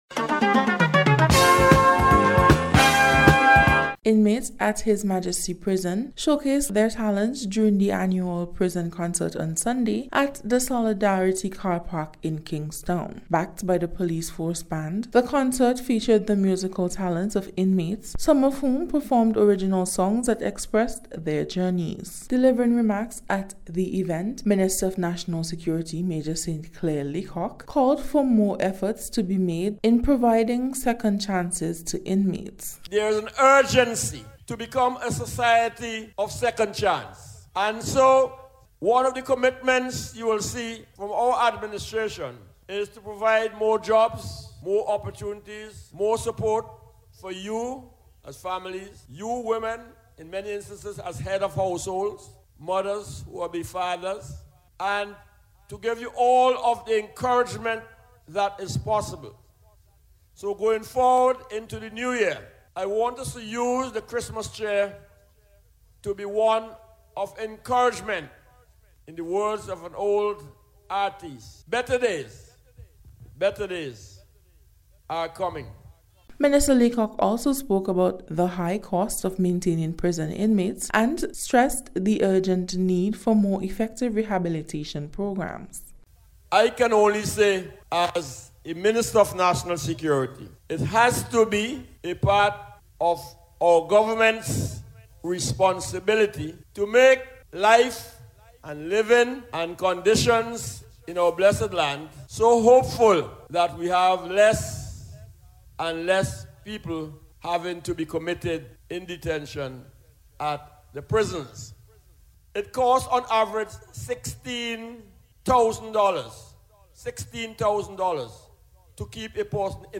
PRISON-CONCERT-REPORT.mp3